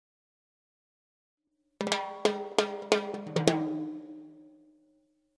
Timbales fills in 135 bpm
The timbales are with light reverb and they are in 135 bpm.
This package contains real timbales fills playing a variety of fills in 135 bpm.
The free 5 samples are already with nice reverb .
The timbales were recorded using “ AKG C-12” mic. The timbales were recorded mono but the files are stereo for faster workflow.